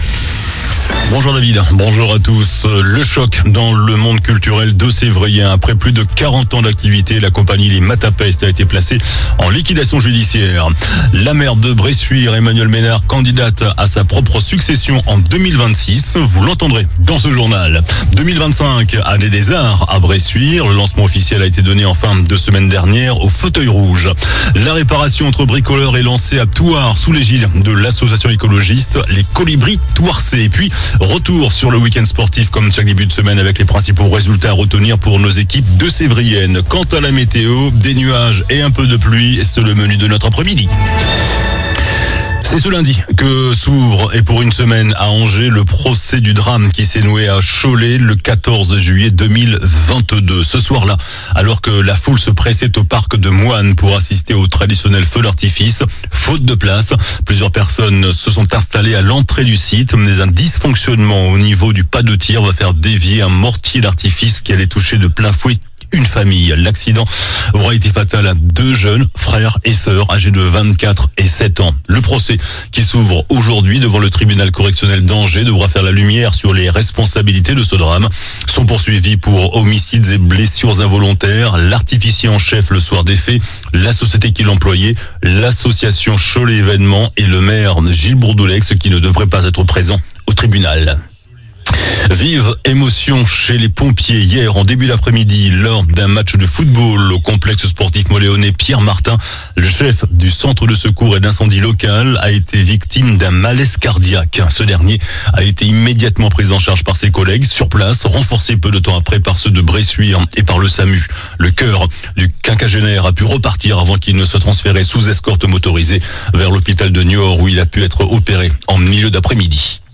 JOURNAL DU LUNDI 24 FEVRIER ( MIDI )